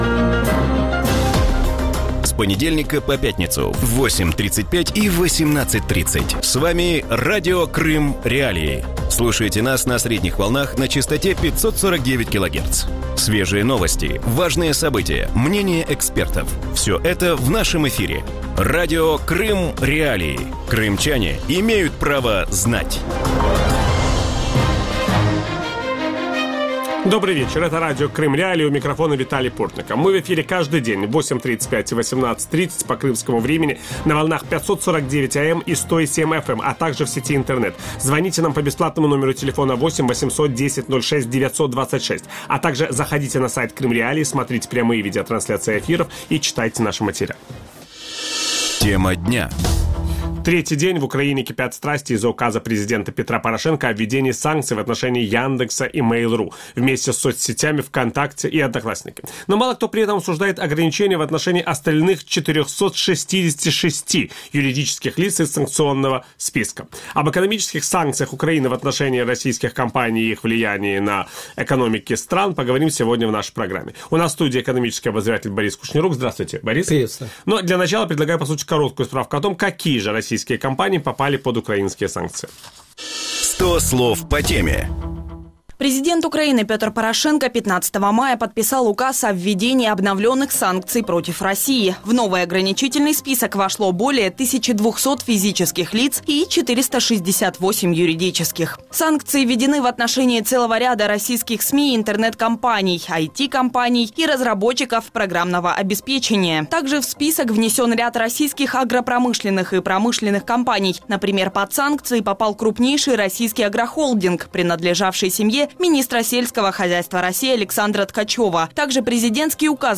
Гость эфира – украинский экономический обозреватель
Ведущий – Виталий Портников.